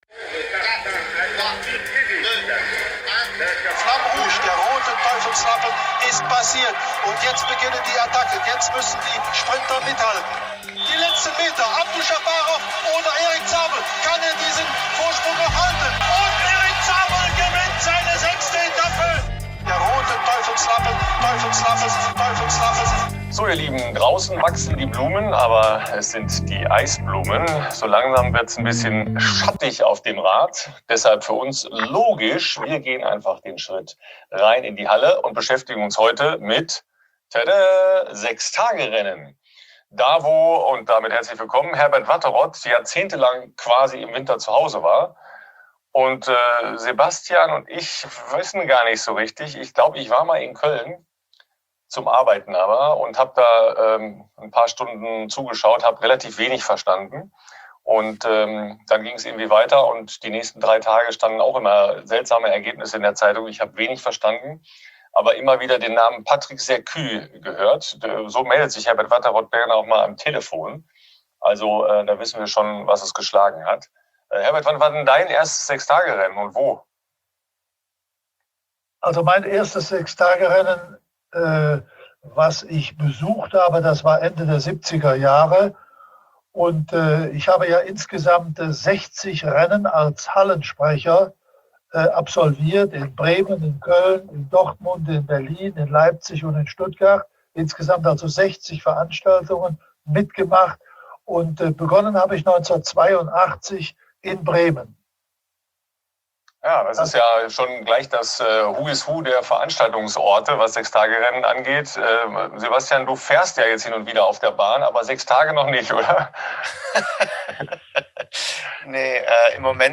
Herbert Watterott kann sehr gut singen!! eine wichtige Facette der 6-Tage-Rennen kann man nur singen...und das tut Herbert. Außerdem natürlich unzählige Anekdoten, Liebeserklärungen, Stars und die Frage nach den Frauen, den Stars und der Zukunft dieser einmaligen Veranstaltungen.